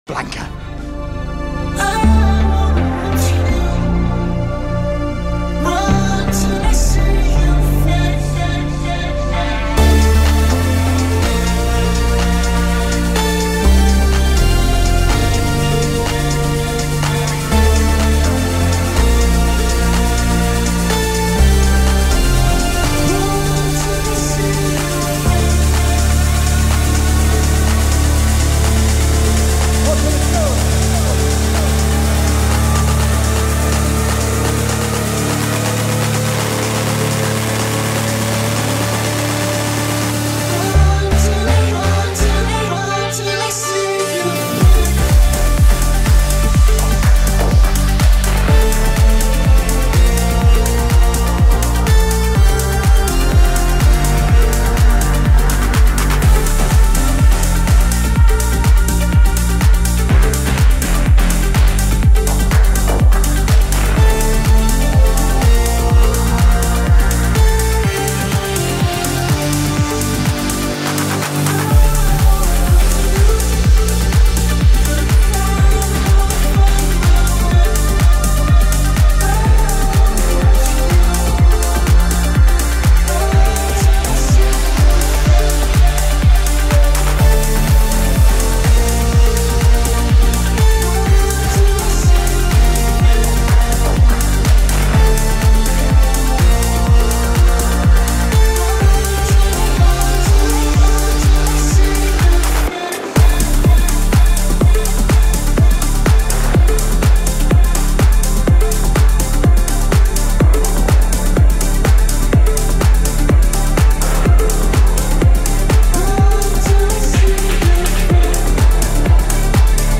This liveset is embedded on this page from an open RSS feed.